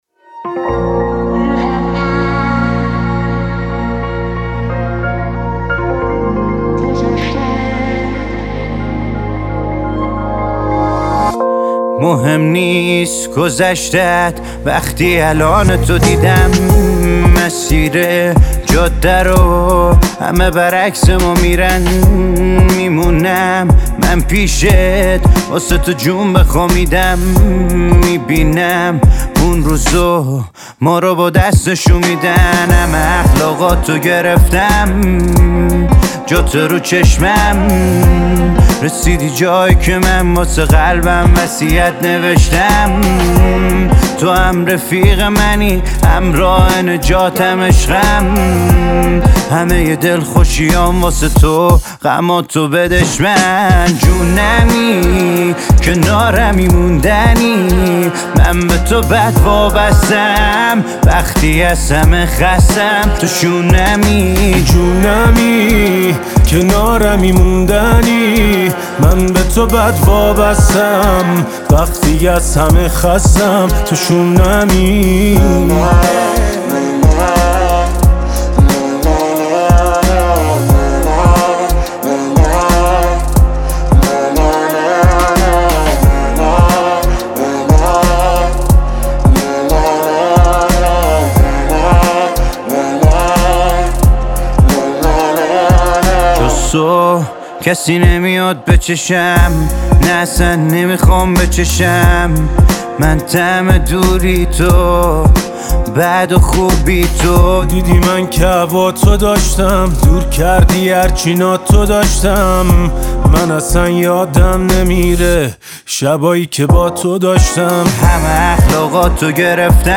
اهنگ غمگین